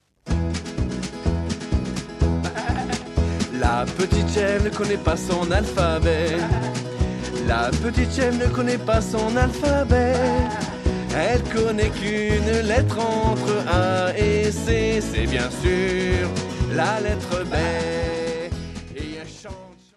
CD de chansons pour enfants